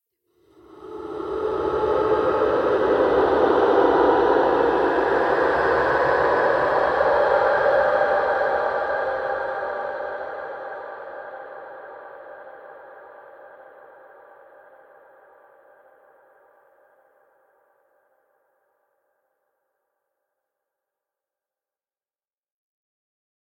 Где то вдалеке призраки шепчут